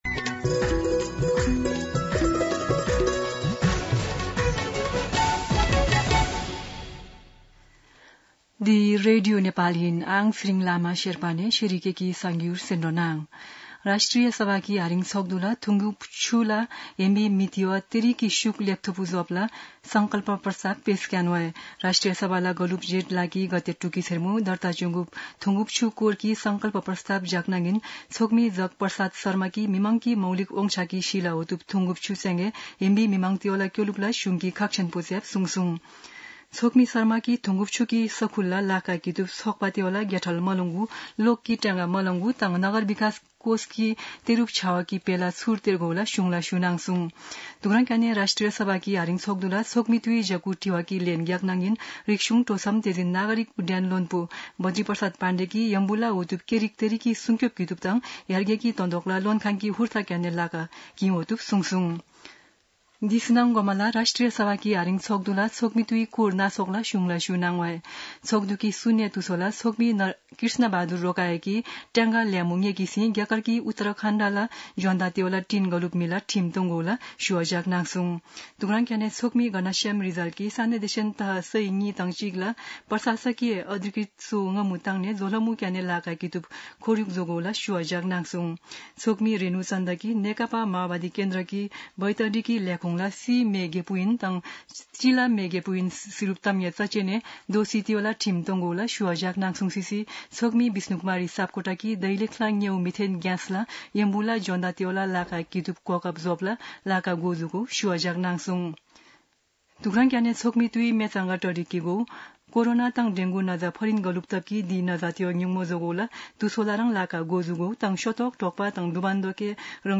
शेर्पा भाषाको समाचार : १२ असार , २०८२
Sherpa-News-3-12.mp3